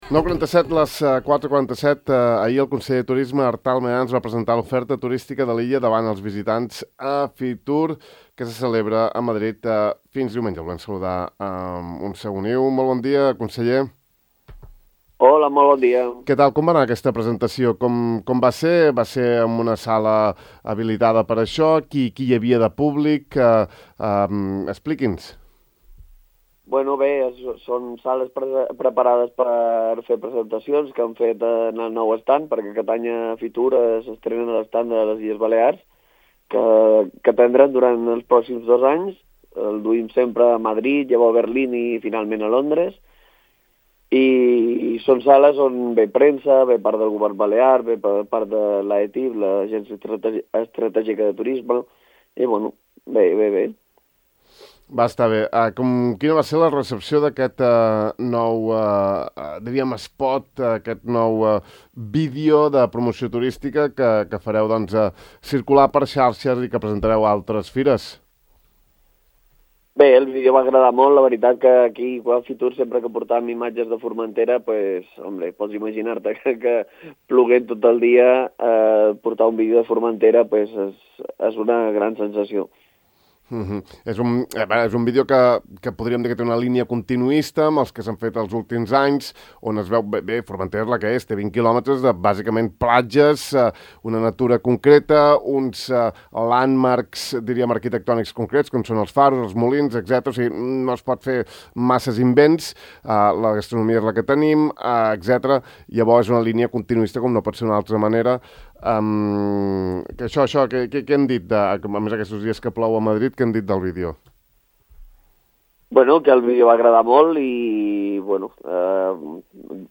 El conseller insular de Turisme, Artal Mayans, ha afirmat a Ràdio Illa que el nou vídeo promocional de Formentera “ha agradat molt a Fitur”, on ha set presentat durant les darreres hores.